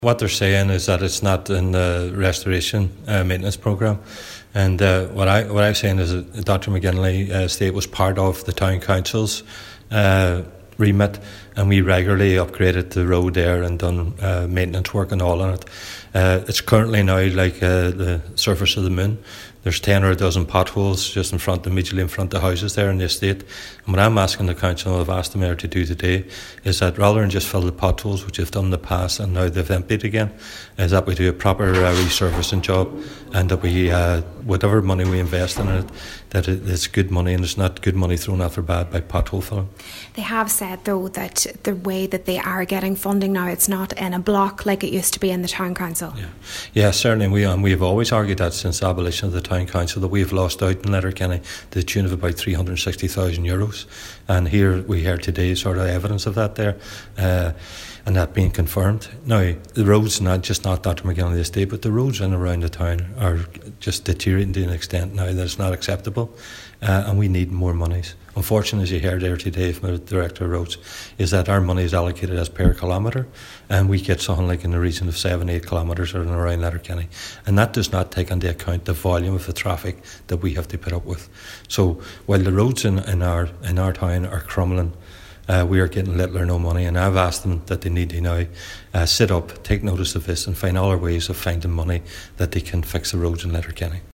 While acknowledging budget constraints, Cllr. McMonagle says the Council need to come up with other ways to fund effective resurfacing works: